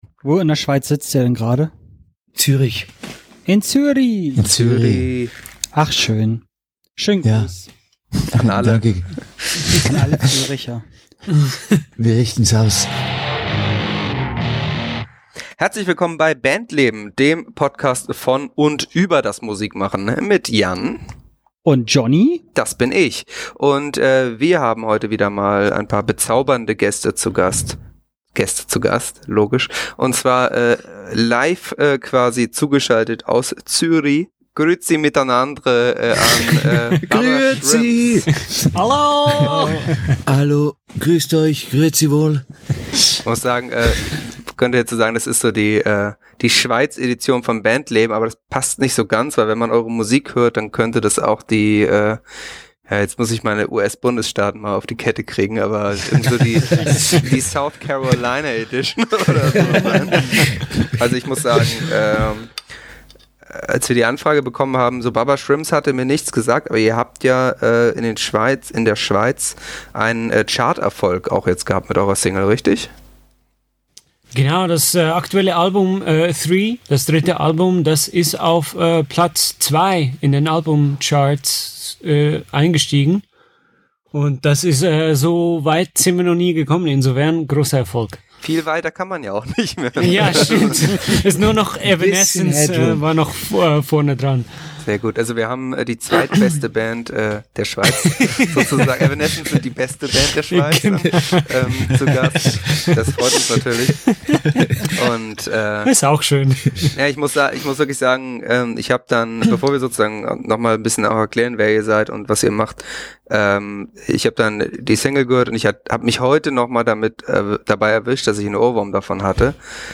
Die drei sympathischen Buben von Baba Shrimps wurden direkt aus Züri ins Bandleben Studio geschaltet